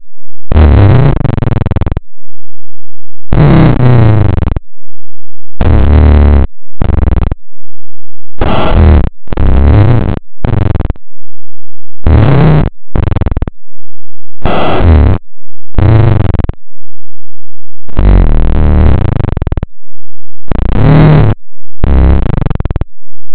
E-PCM
Имеем небольшую такую прибавочку ДД в 66 дБ и немного прибавочку в качестве, если это можно назвать немного.
С более высокой частотой дискретизации качество было бы лучше, но копилка не резиновая.